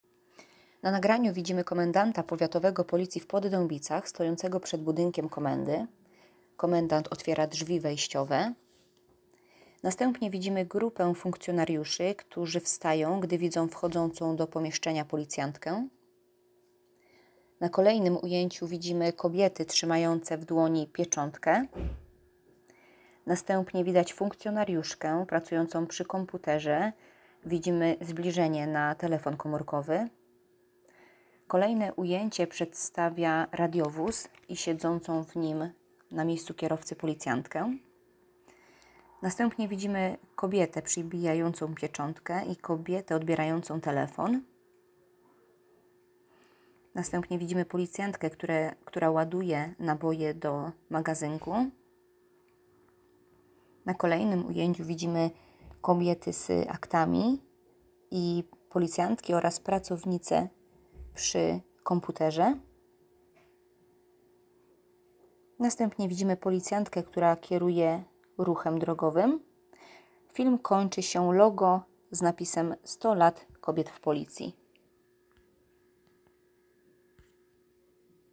Nagranie audio audiodeskrypcja.m4a